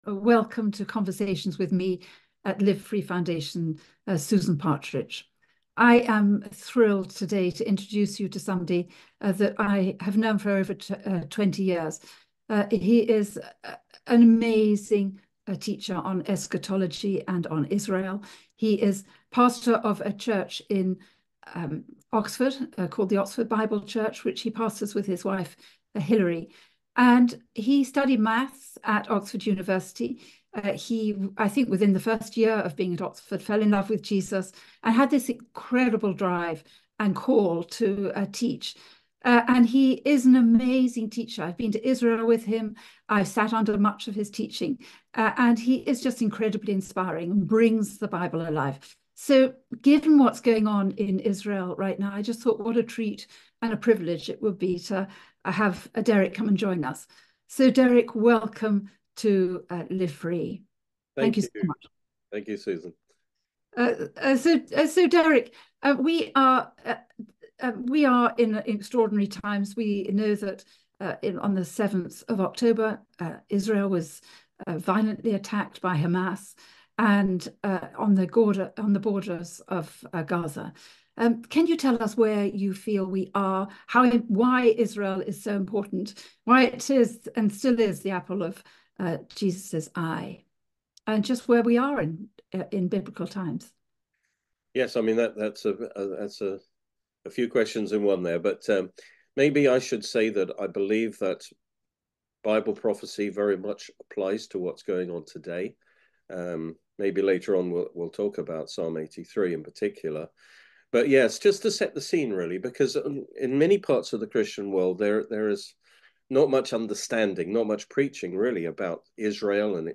for an insightful discussion on the significance of Israel in biblical prophecy. They delve into the events surrounding Israel and how they align with Scripture, including an analysis of Psalm 83 and Ezekiel 38. Discover the spiritual and historical context of recent conflicts and the future of Israel through the lens of biblical prophecy.